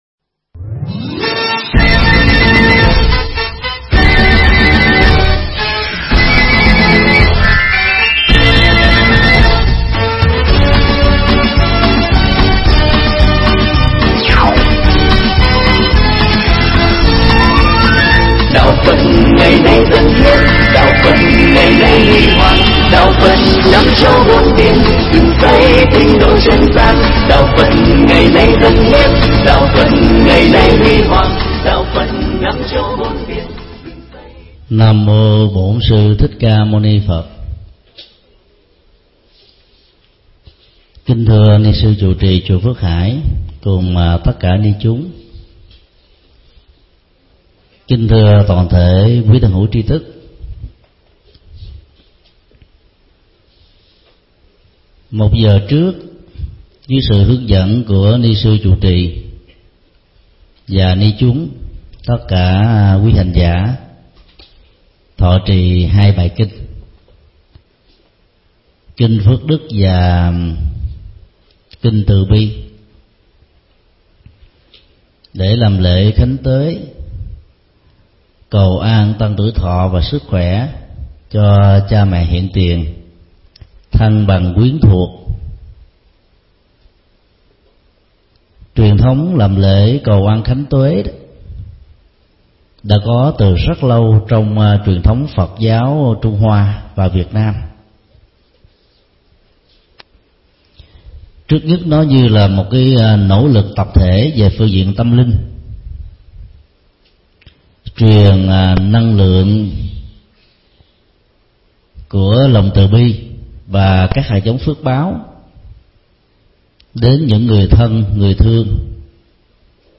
Mp3 Thuyết Giảng Cầu an khánh tuế – Thượng Tọa Thích Nhật Từ Giảng tại Chùa Phước Hải, Chalortte, NC, ngày 16 tháng 8 năm 2008